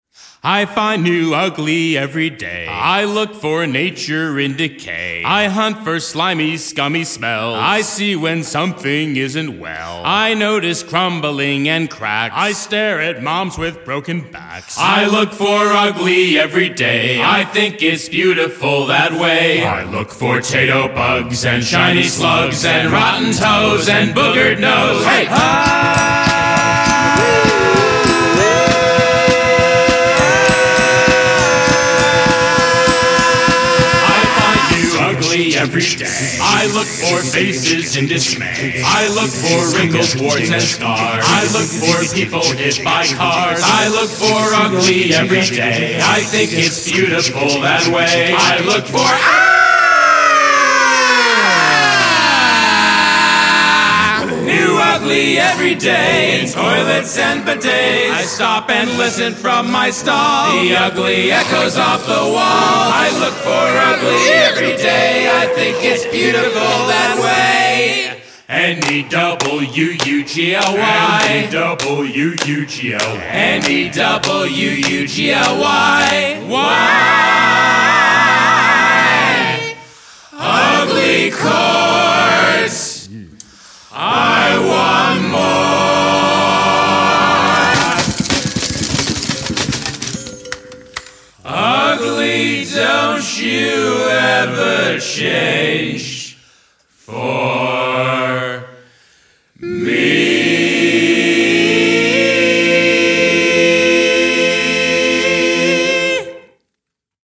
Only Percussion and Voice (no pianos allowed)